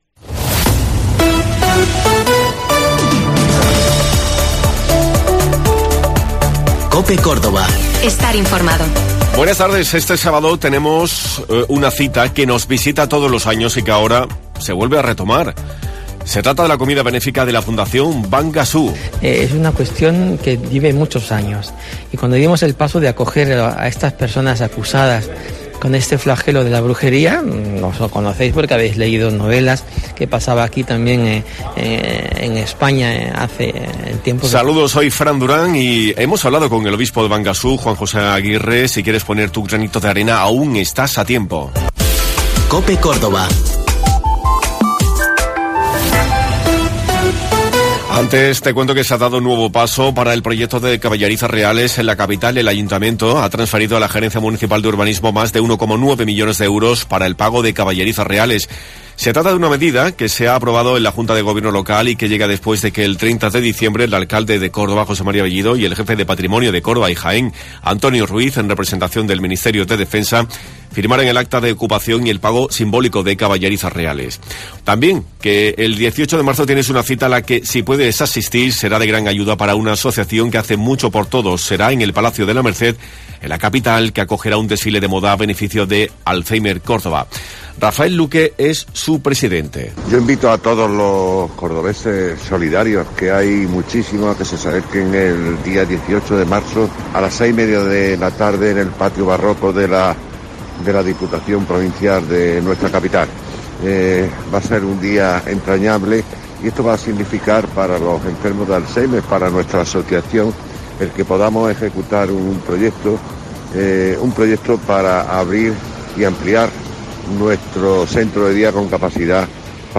Este sábado vuelve la tradicional comida organizada por la Fundación Bangassou para poder llevar a cabo los proyectos que mejoren las vidas de muchas personas en esta región de África. Hoy hemos hablado con el obispo de Bangassou, Juan José Aguirre.